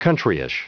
Prononciation du mot : countryish
countryish.wav